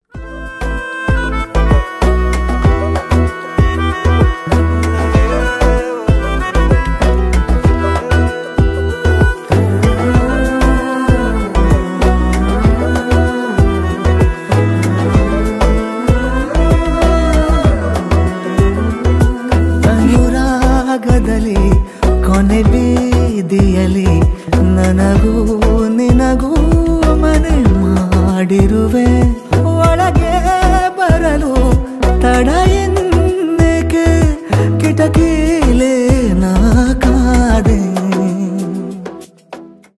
инструментальные , индийские